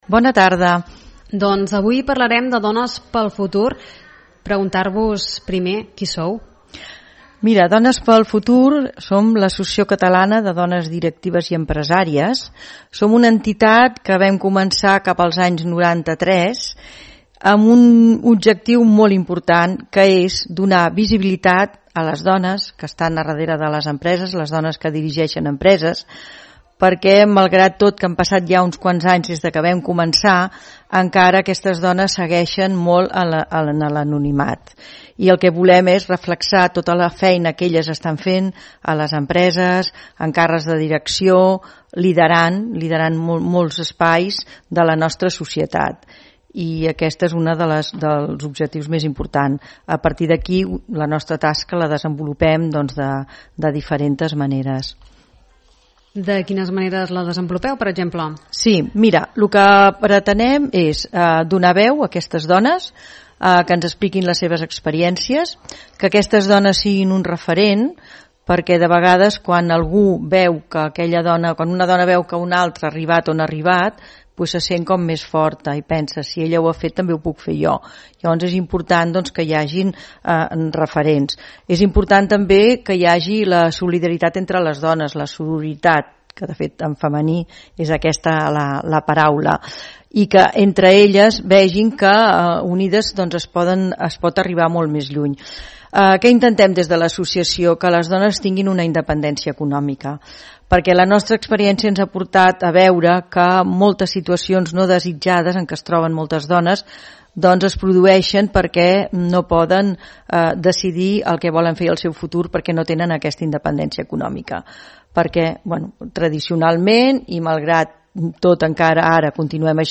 001-ENTERVISTA-EMPRENEDORA.mp3